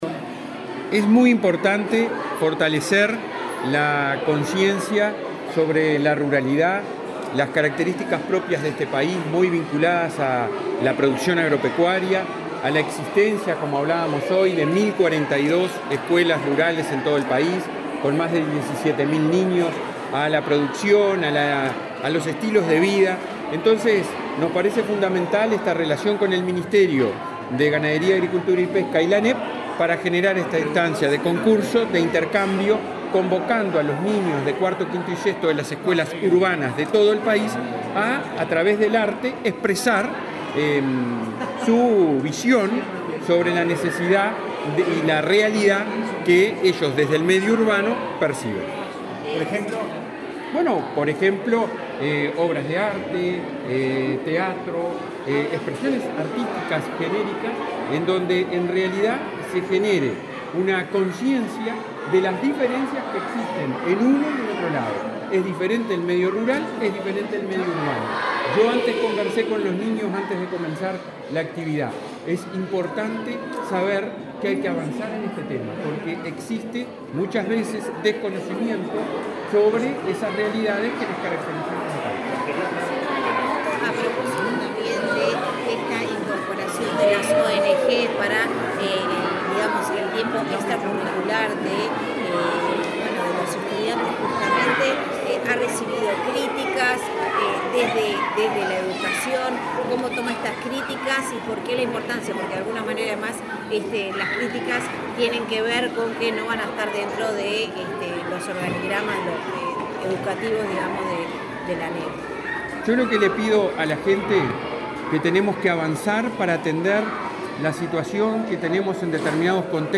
Declaraciones del presidente del Codicen, Robert Silva
Declaraciones del presidente del Codicen, Robert Silva 21/07/2022 Compartir Facebook X Copiar enlace WhatsApp LinkedIn Este jueves 21, el ministro de Ganadería, Fernando Mattos, y el presidente del Codicen, Robert Silva, participaron del lanzamiento del concurso de la ANEP "Aprendemos Ruralidad". Luego, Silva dialogó con la prensa.